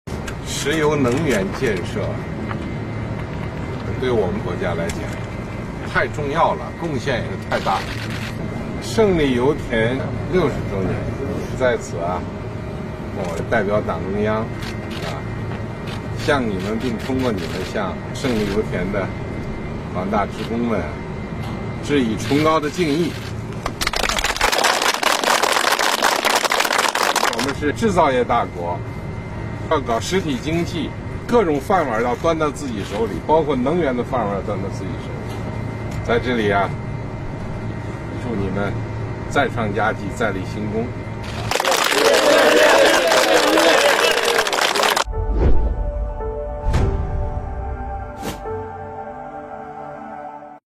21日下午，习近平总书记来到胜利油田，登上钻井平台，察看作业情况，看望慰问石油工人。